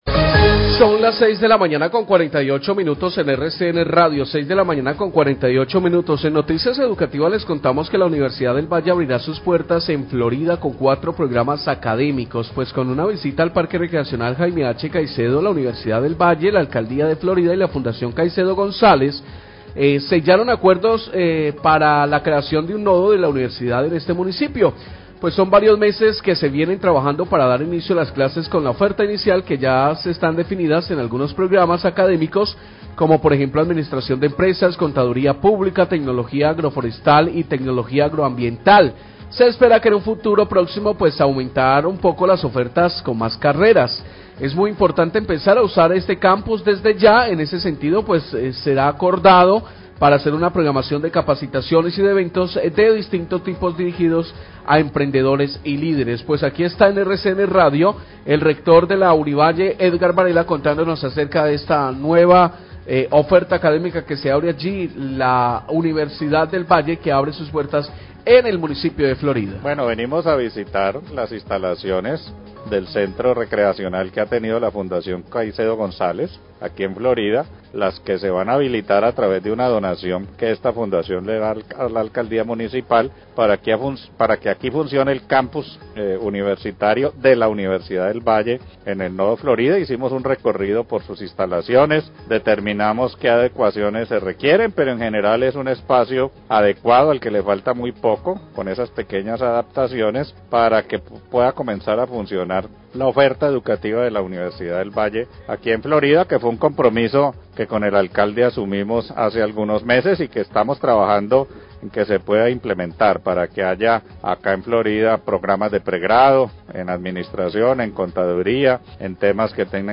Radio
El rector de la Universidad del Valle, Edgar Varela, habla sobre la nueva sede de la universidad que funcionará en Florida gracias a la donación del terreno donde funcionaba el parque recreacional de la Fundación Caicedo González.